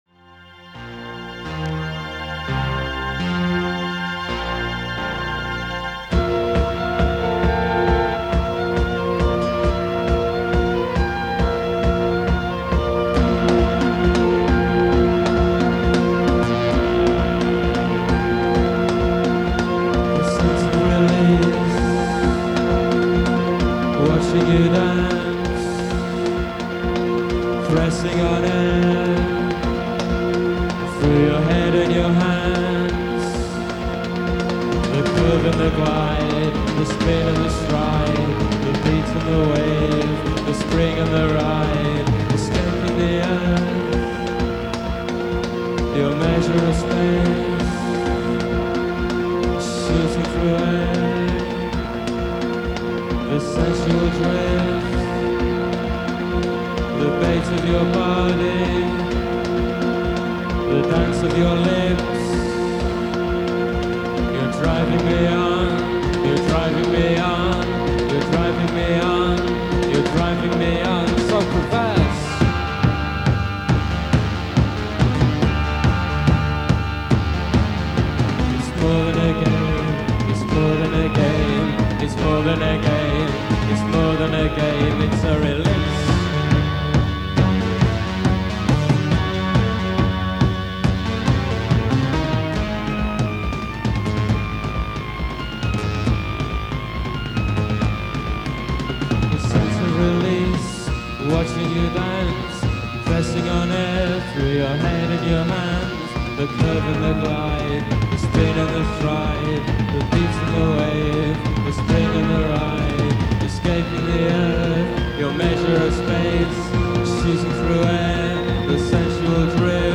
In Concert at The Ritz, Manchester
post-punk/Experimental